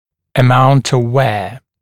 [ə’maunt əv weə][э’маунт ов уэа]продолжительность ношения